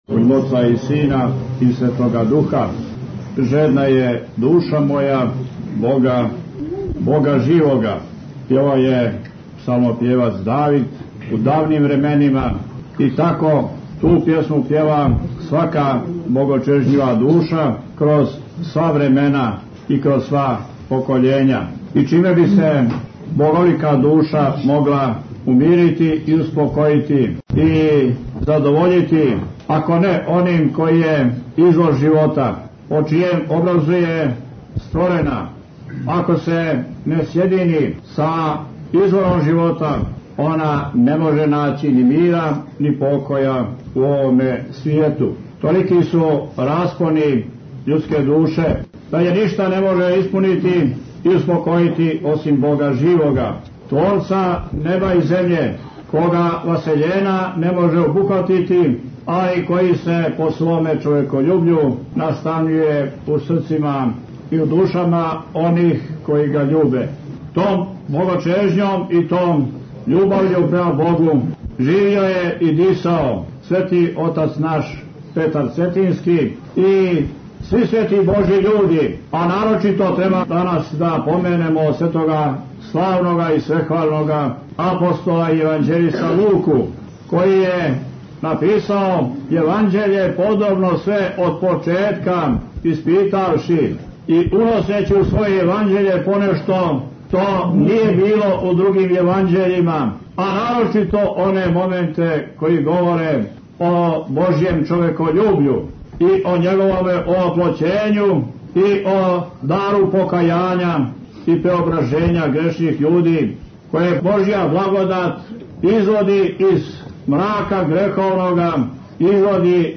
Бесједа са Литургије у Цетињском манастиру од 31. октобра, Преосвећеног Епископа Будимљанско-никшићког Г. Јоаникија | Радио Светигора
Tagged: Бесједе